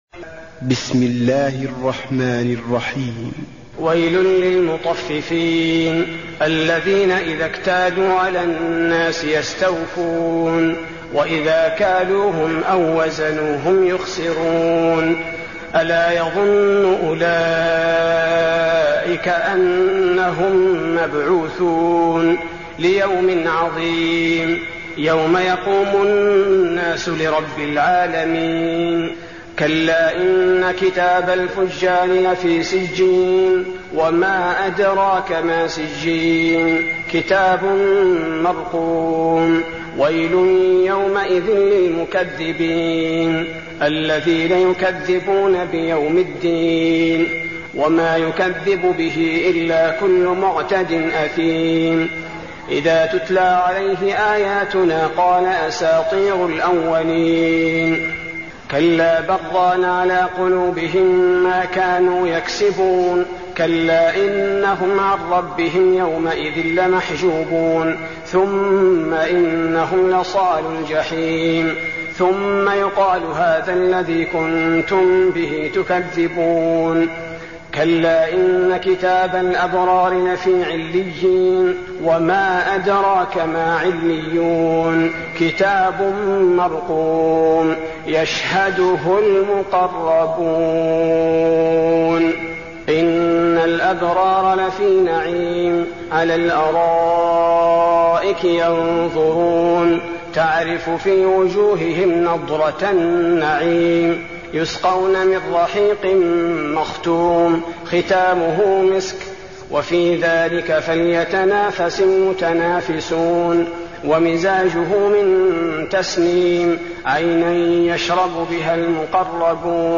المكان: المسجد النبوي المطففين